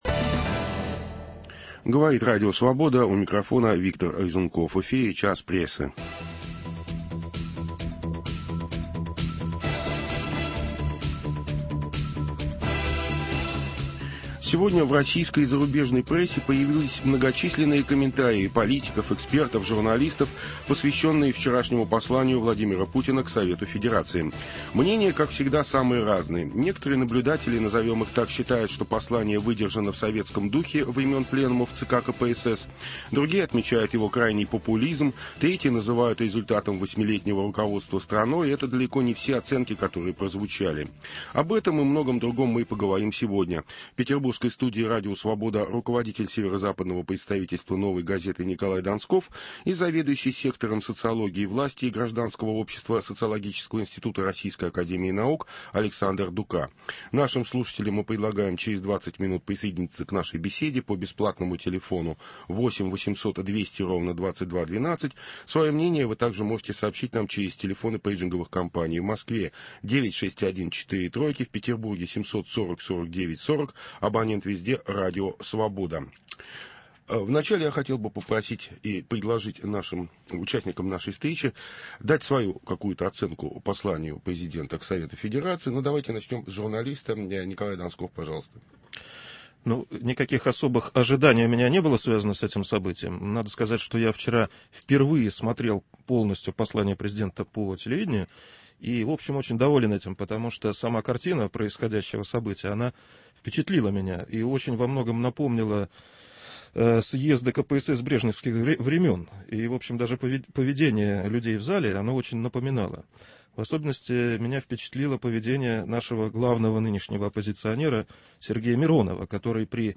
Послание Владимира Путина к Федеральному Собранию сквозь призму публикаций в российской и иностранной прессе. Беседуют